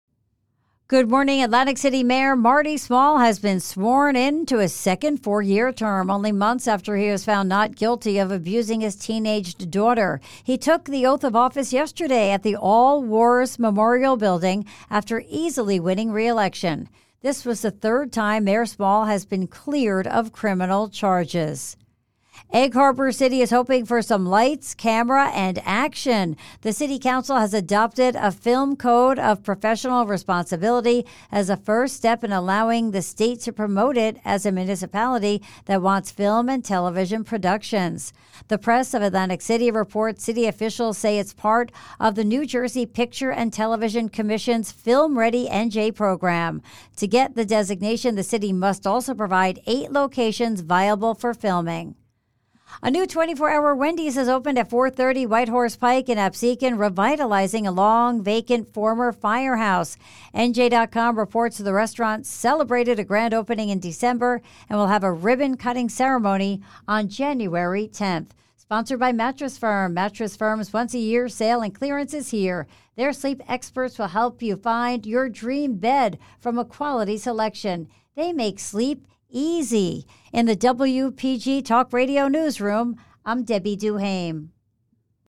WPG Talk Radio 95.5FM South Jersey’s Talk Station News update